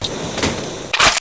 ak47m_clipin.wav